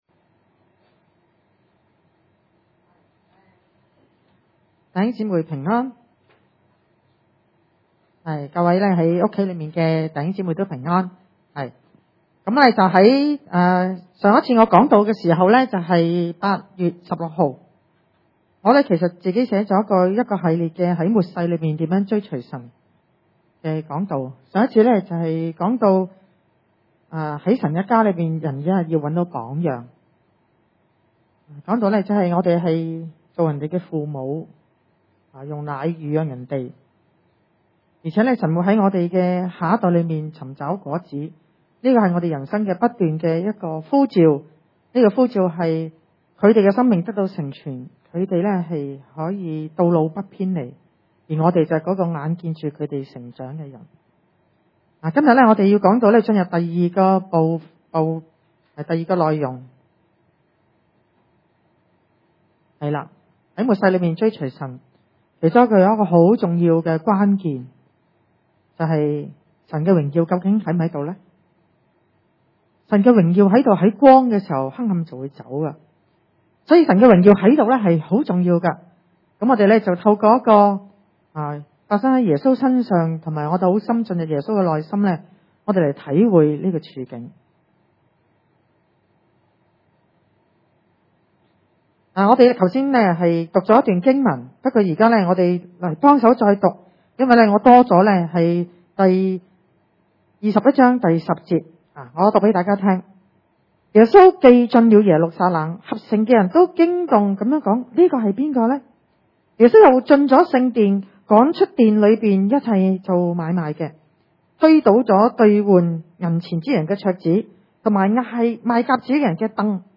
經文: 馬太福音 21:12-17 崇拜類別: 主日午堂崇拜 潔淨聖殿 12 耶穌進了神的殿，趕出殿裏一切做買賣的人，推倒兌換銀錢之人的桌子，和賣鴿子之人的凳子， 13 對他們說：「 經上 記着說： 我的殿必稱為禱告的殿， 你們倒使它成為賊窩了。」